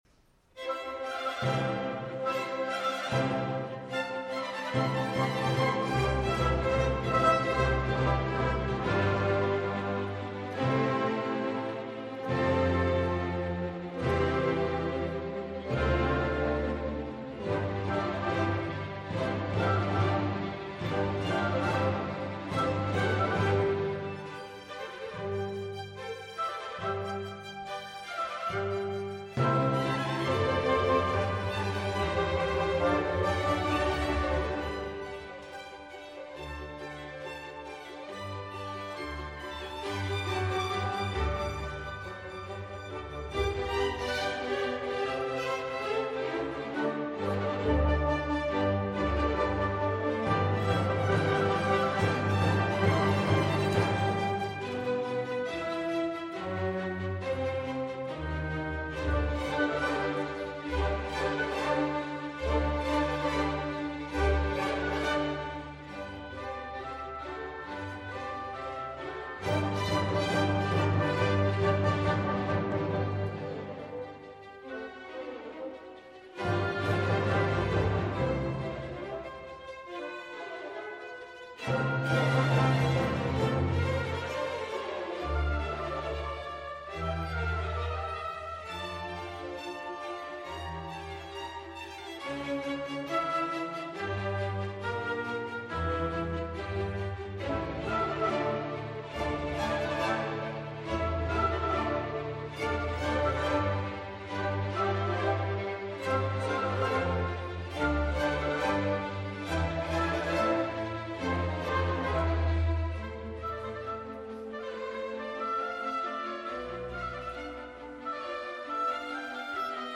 Μουσικά Σύνολα ΕΡΤ – Εθνική Συμφωνική Ορχήστρα
Ωδείο Αθηνών – 17 Ιανουαρίου 2024